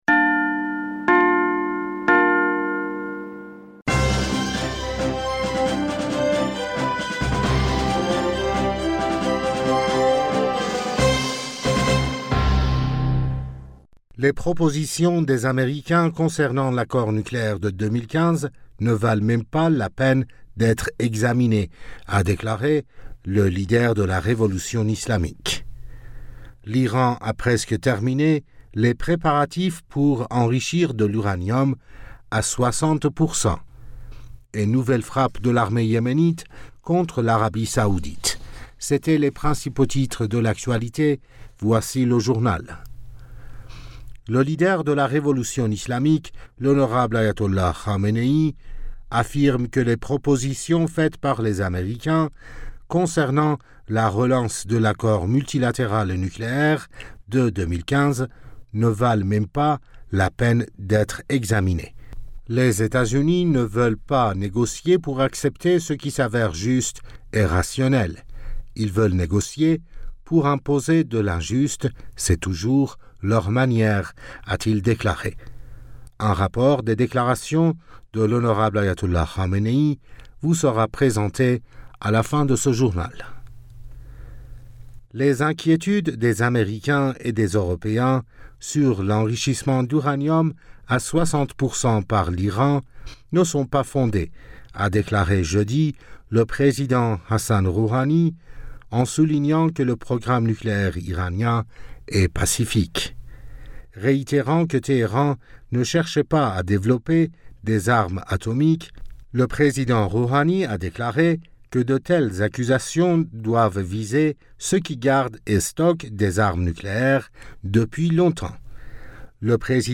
Bulletin d'information du 15 Avril 2021